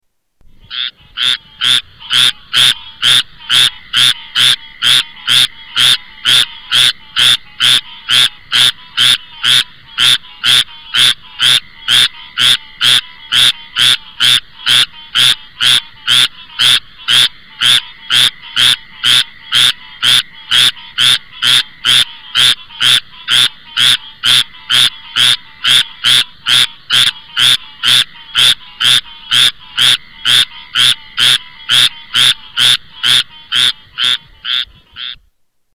Squirrel Treefrog